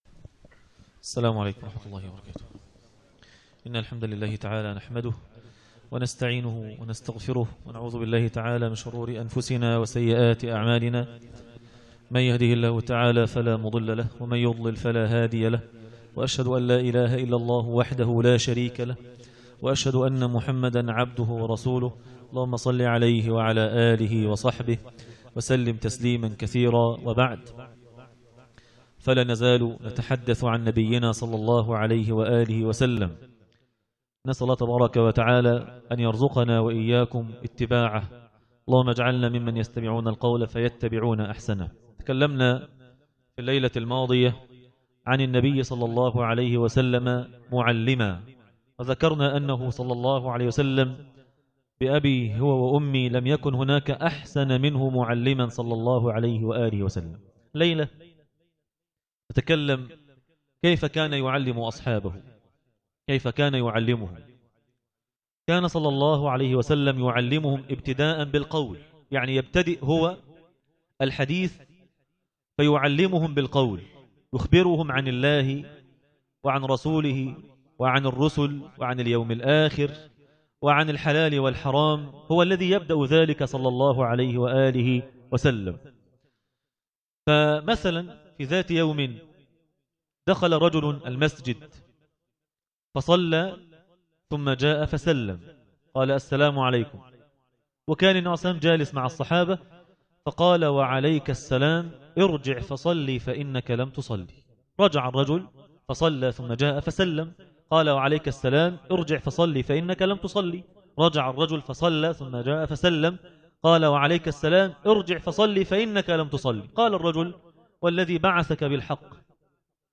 النبي (صلي الله عليه وسلم ) معلما - الجزء الثاني- درس التراويح ليلة 10 رمضان1437هـ